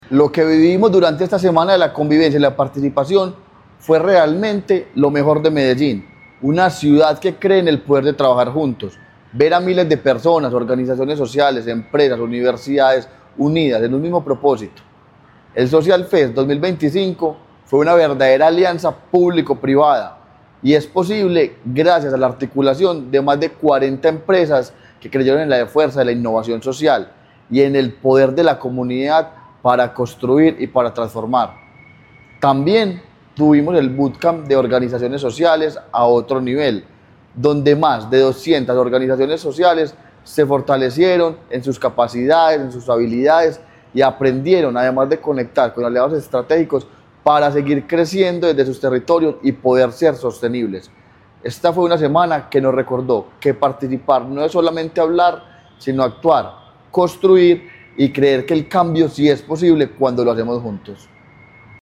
Semanaconvivencia_participacion_secretario.mp3